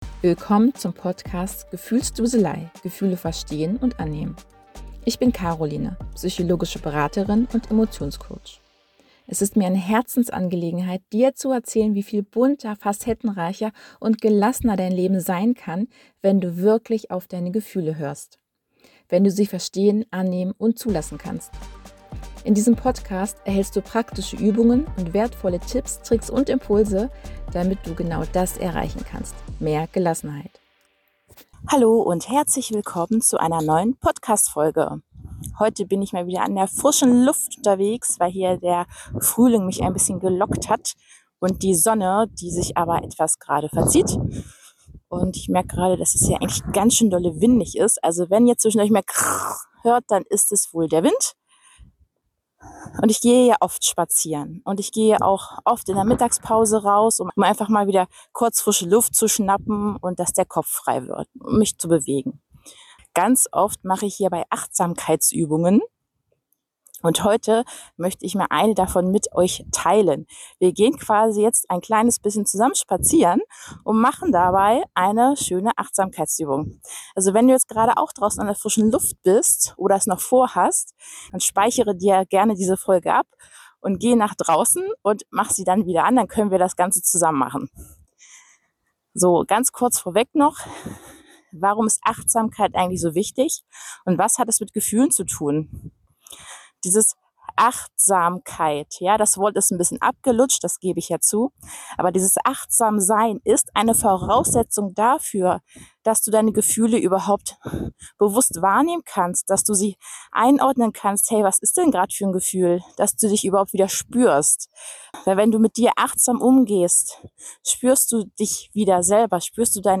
In dieser Podcastfolge stelle ich dir bei einem Frühlingsspaziergang meine Lieblingsübung zur Schulung von Achtsamkeit vor.
Gemeinsamer Frühlingsspaziergang für die Ohren